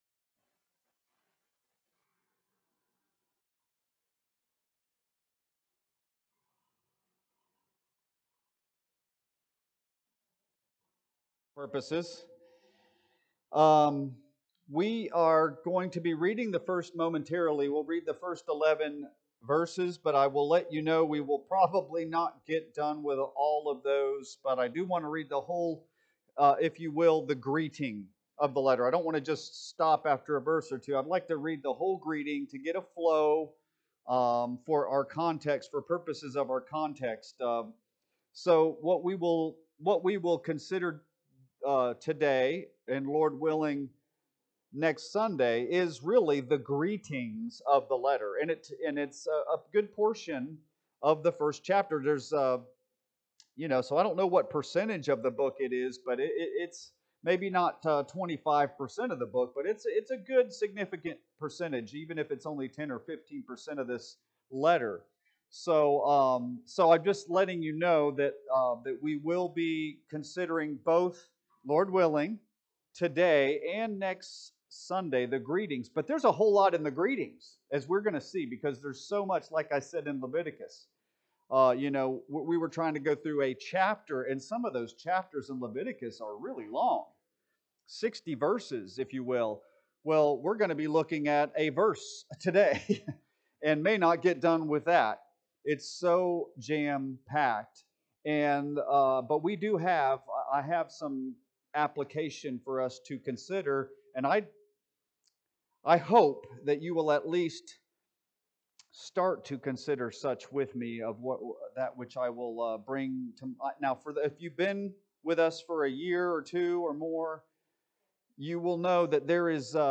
Sunday Morning Sermon Series: “Captivated by Christ” Phil 1:1-11